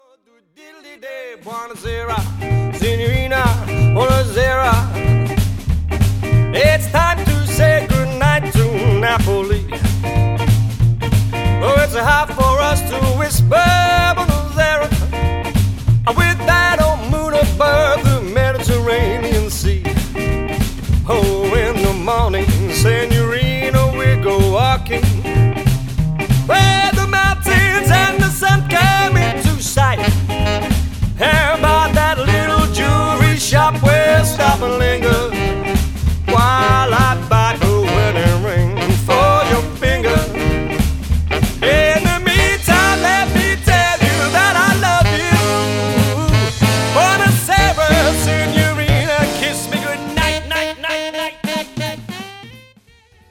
• Classic swing jazz and jive band
• Five-piece instrumental line-up
• Guitar, bass, drums, trumpet, sax, vocals